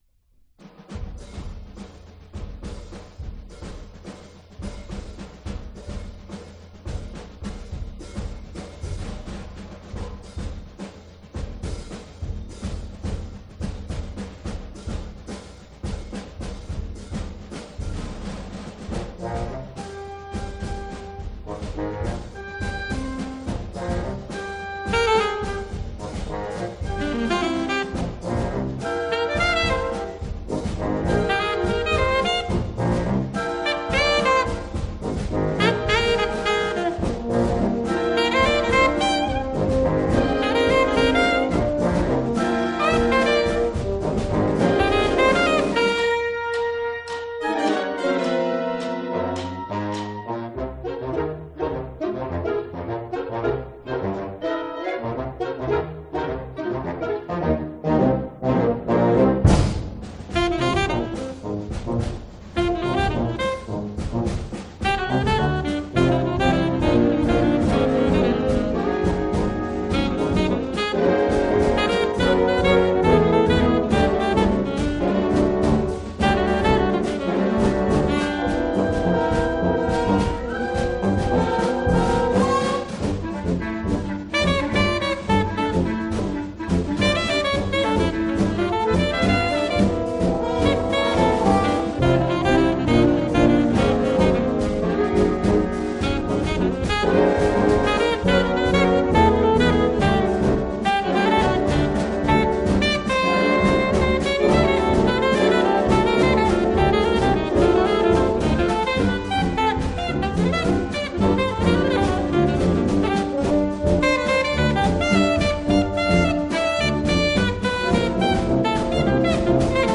'Swamp Stomp'/Damani Phillips - Snare Trap Set with Grinnell Symphony Orchestra & Central Iowa Wind Ensemble